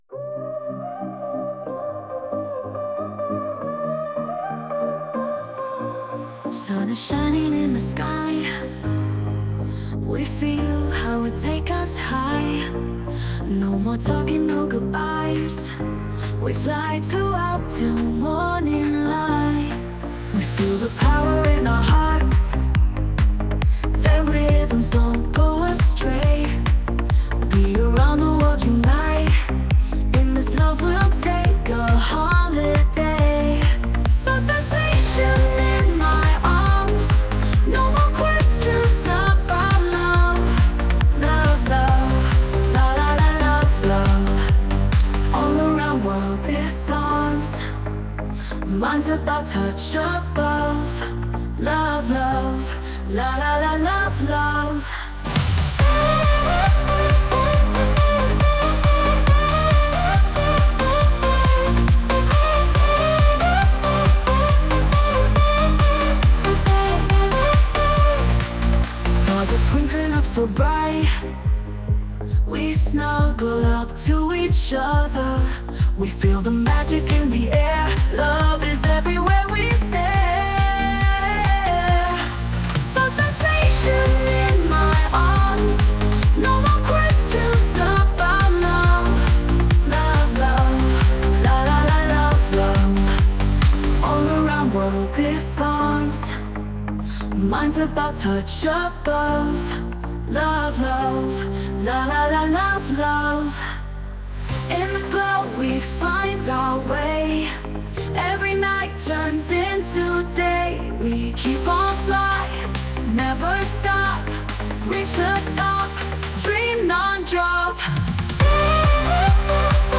СИНТИ-ПОП, ТРАНС, ХАУС, ДАБСТЕП
(Vocal Trance version, Unmastered version, 2024)